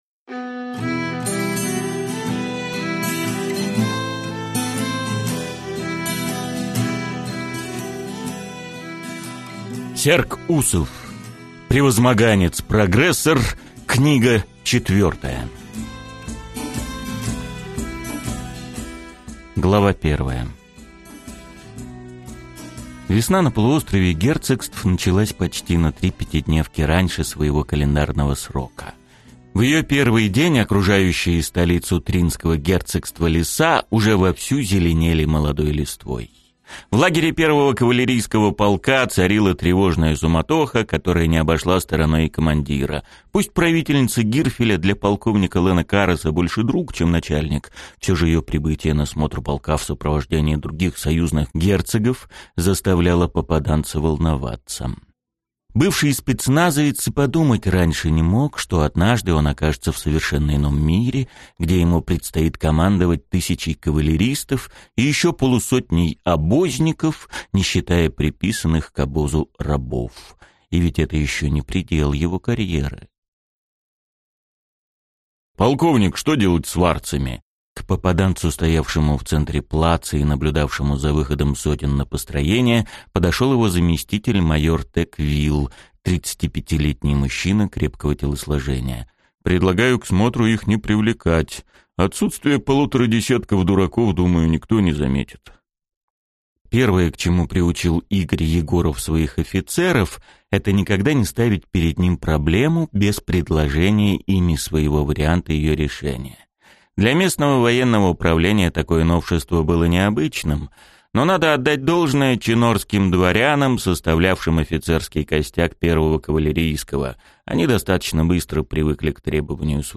Аудиокнига Превозмоганец-прогрессор. Книга 4 | Библиотека аудиокниг